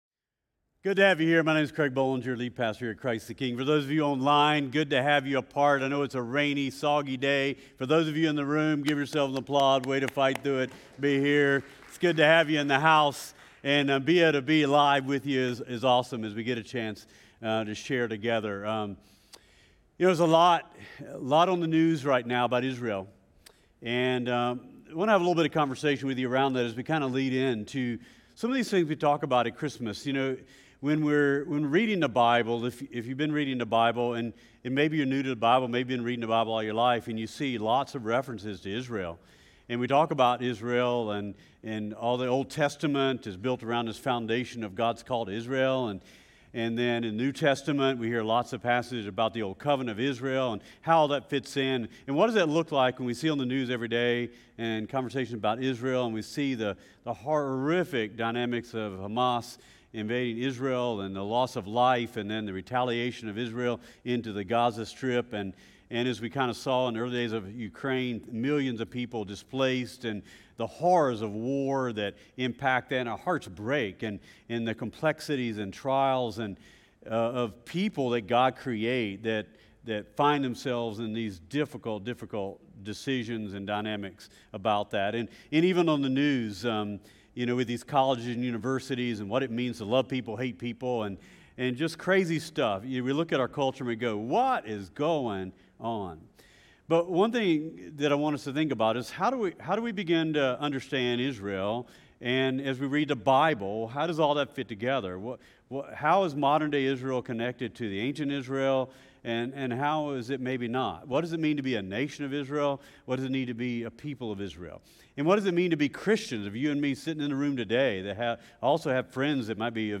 CtK-Sermon.mp3